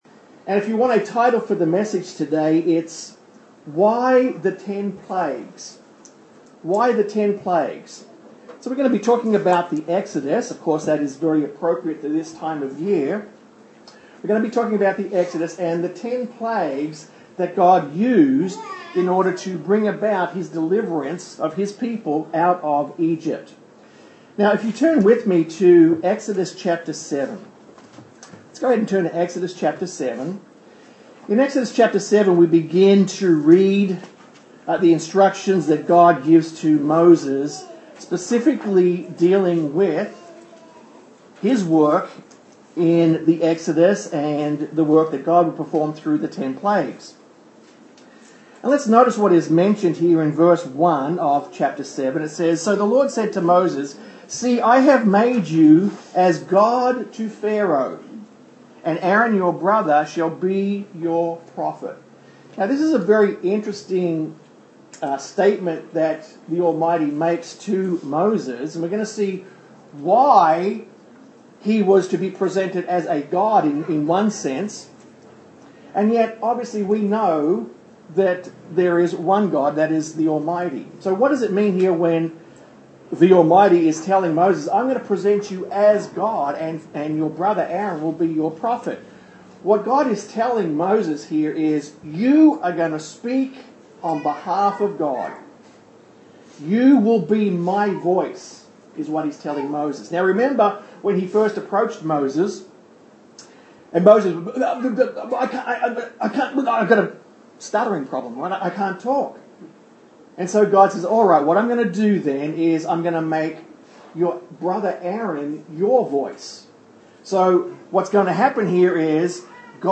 Sermons
Given in Austin, TX